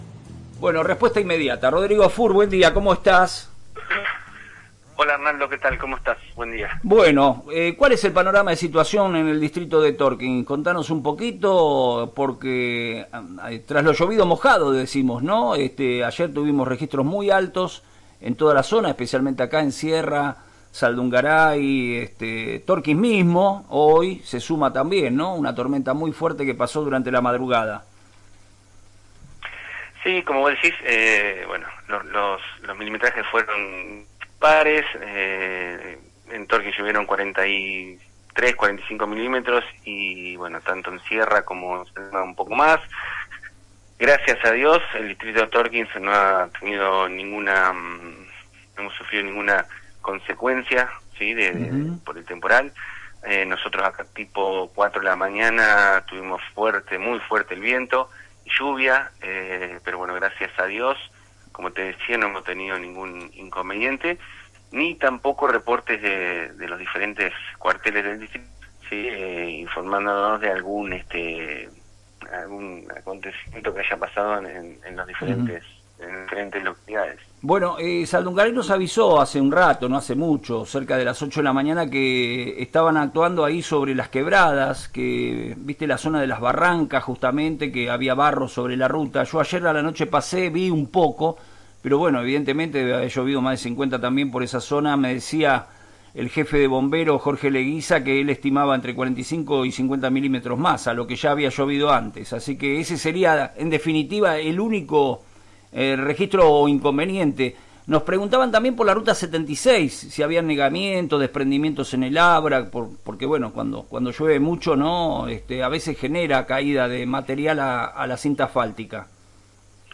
Rodrigo Fuhr, director de Defensa Civil de Tornquist, brindó un informe detallado sobre la situación del distrito tras el intenso fenómeno meteorológico que azotó la región. En diálogo con FM Reflejos, el funcionario destacó que, si bien se registraron milimetrajes importantes y ráfagas de viento considerables durante la madrugada, no se reportaron incidentes de gravedad ni daños personales en ninguna de las localidades.